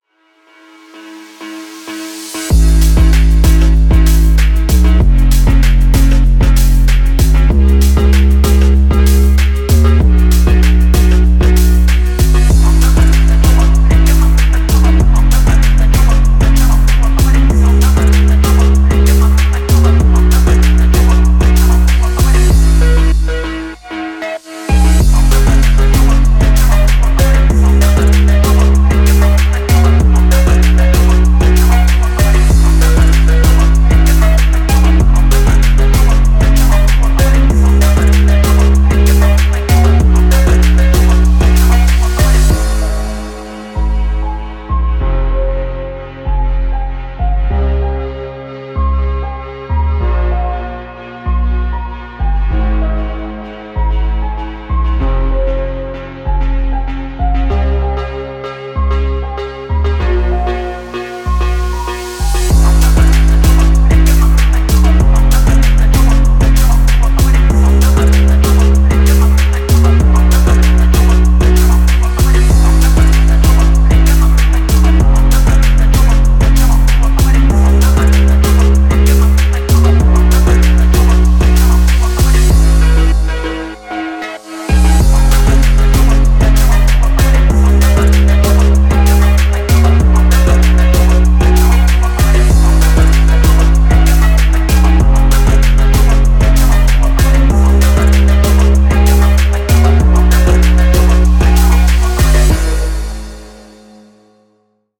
Genre: hiphop.